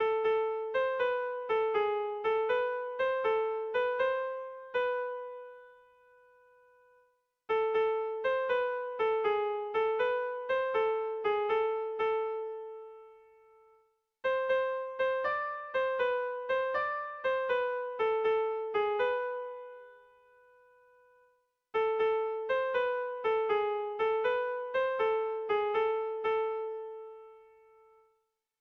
Sentimenduzkoa
Zortziko txikia (hg) / Lau puntuko txikia (ip)
A1A2BA2